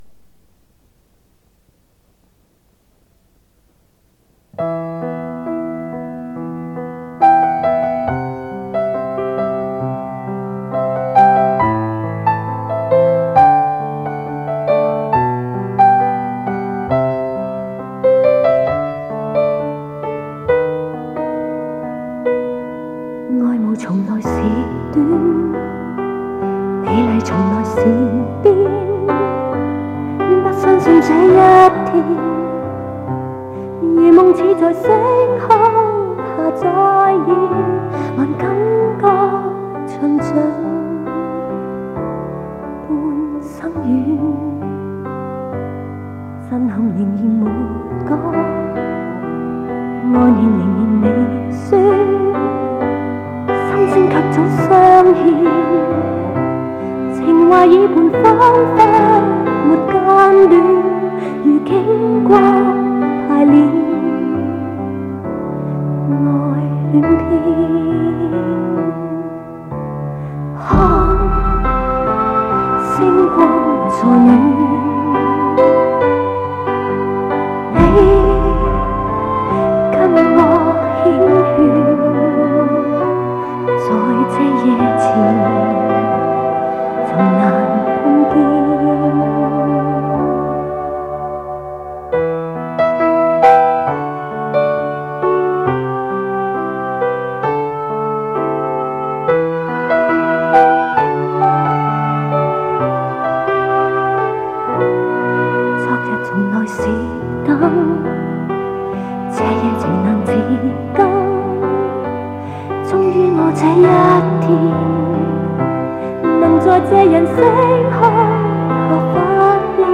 磁带数字化：2022-11-18